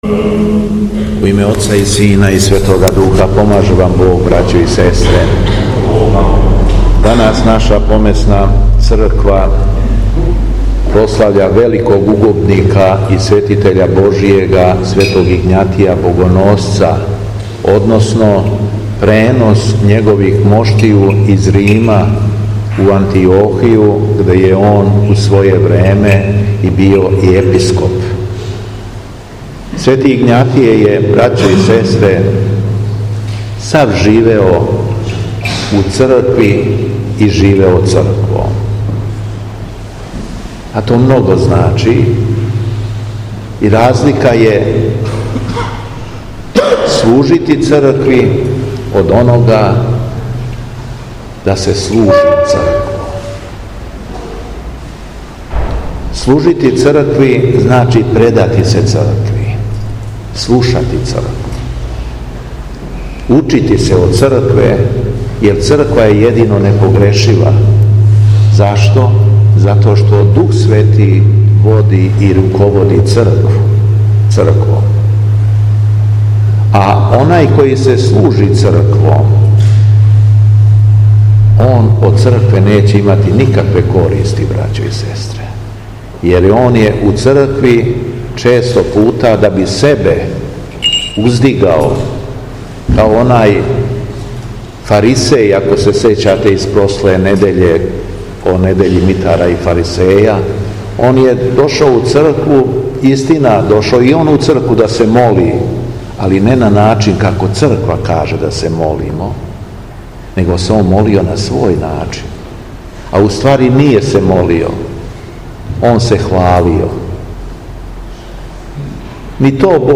Дана 11. фебруара 2023. године када наша Црква молитвено прославља Пренос моштију Светог Игњатија Богоносца, Његово Преосвештенство Епископ шумадијски Господин Јован служио је свету архијерејску Литургију у храму Свете Петке у Смедеревској Паланци.
Беседа Његовог Преосвештенства Епископа шумадијског г. Јована